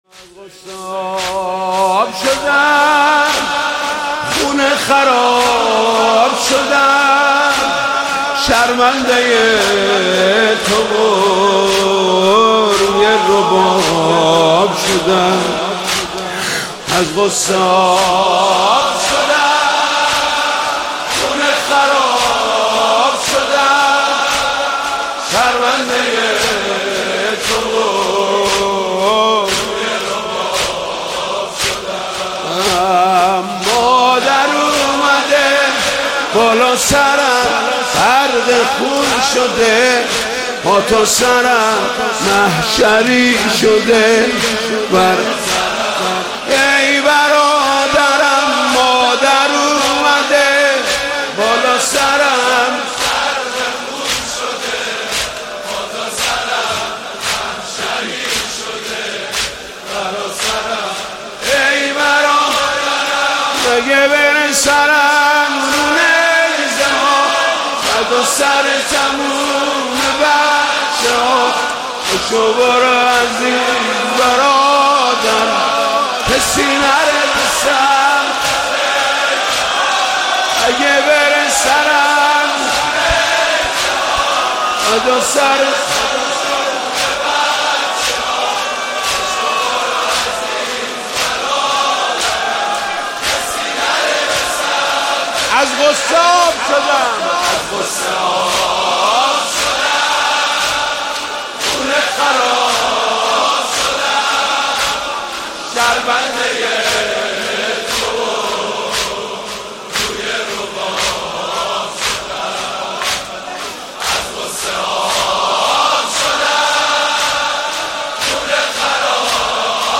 مداحی حماسی